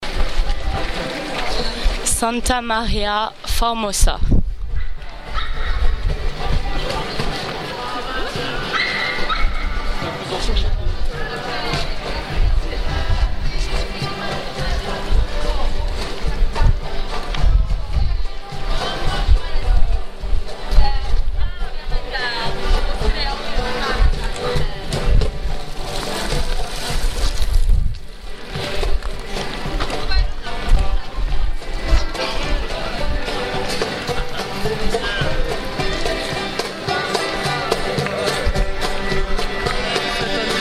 un groupe de chanteur sur la place de Santa Maria formosa (+ en prime l'aboiement d'un petit chien)